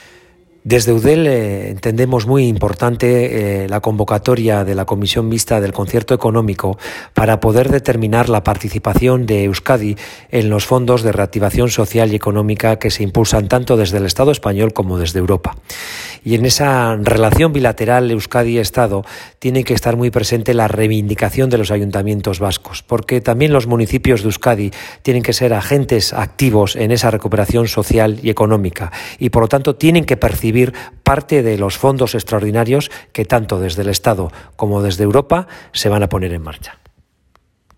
AUDIOS GORKA URTARAN: